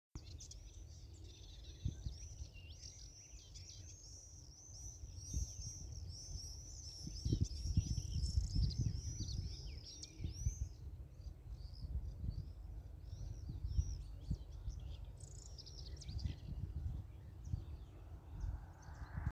черный стриж, Apus apus
ПримечанияPļavā pie ezera